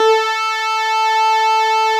snes_synth_057.wav